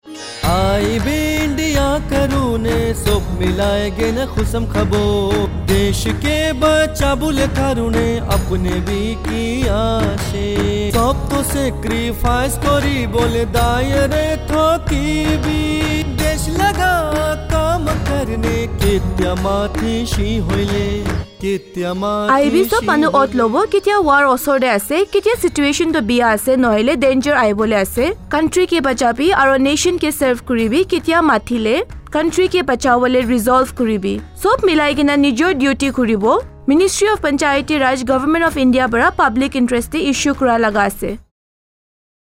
171 Fundamental Duty 4th Fundamental Duty Defend the country and render national services when called upon Radio Jingle Nagamese